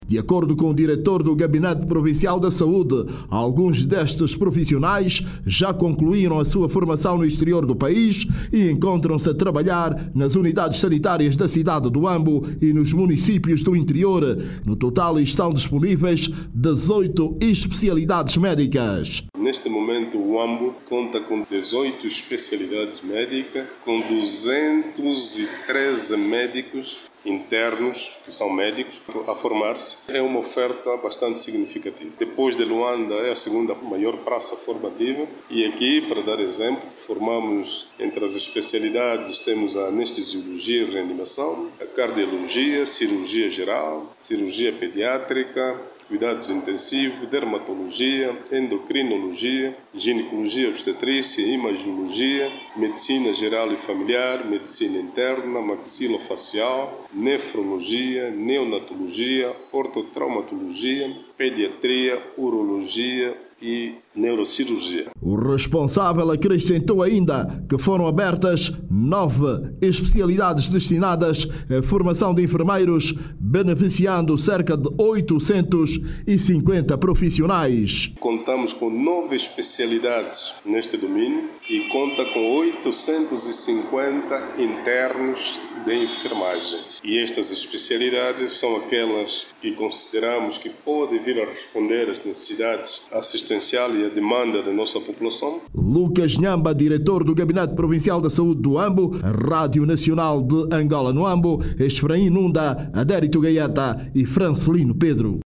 Mais de 200 médicos e enfermeiros estão a receber formação especializada em vários ramos da saúde na Província do Huambo. O sector da saúde na província, aposta na formação especializada com o objectivo de melhorar a qualidade de atendimento a população. Saiba mais dados no áudio abaixo com o repórter